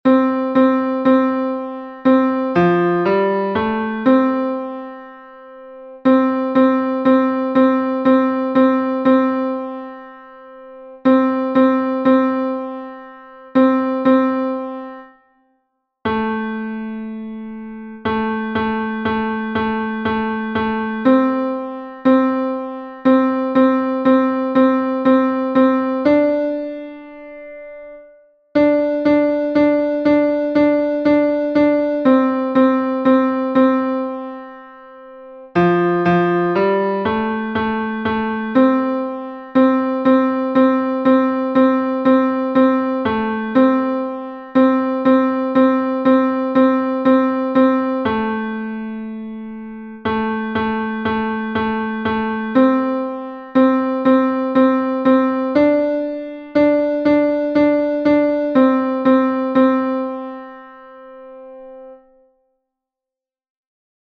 tenors-mp3 1er février 2021